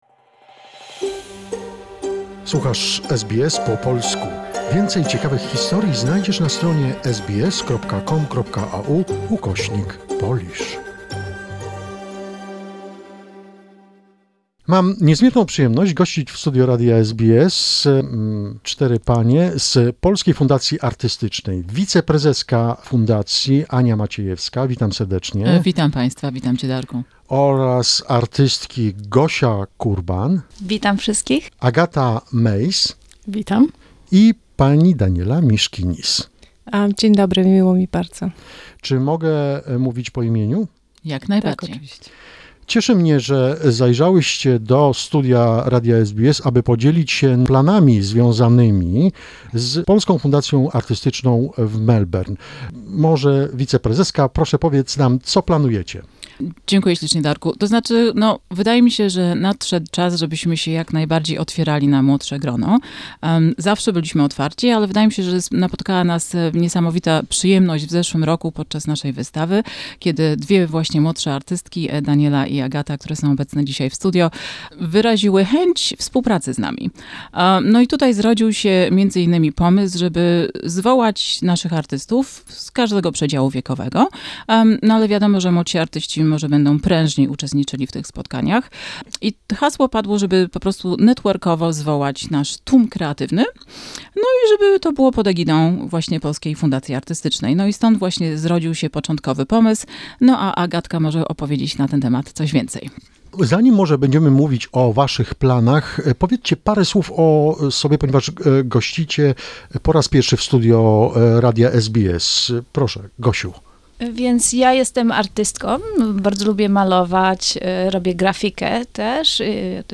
Artists from Polish Art Foundation talk about a new initiative - a meeting of new, young artists, on Sundays, 23 May 2021 ....details in a conversation.